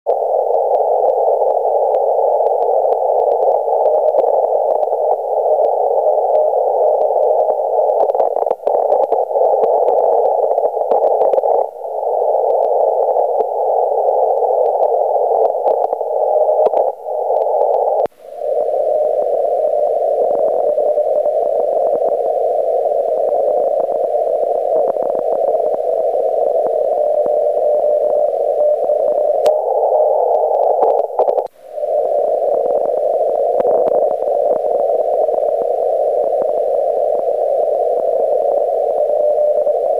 もったいぶるほどのサンプルはないんですが、先日のARRL160mでちょっとだけ録音してきた950とR-4Cの聞き比べです。
R-4C : AGCはF、RF GAIN最大、AF GAINは3時位置、ルーフィングフィルタ装着IFは500HzAF回路入れ替え給電は117V、など
スタートから約18秒までは950、そこから29秒まではR-4C、そして31秒まで950でそこから最後まではR-4Cです。こうやって聴くと違いが分かりにくいかもですが、ご注目いただきたいのはノイズが入った時の差でして、950だと聞き取れないのにR-4Cだと充分聞けるというのがお分かりいただけるでしょうか。逆に、ノイズがない場合はそれほど差がないとも言えます。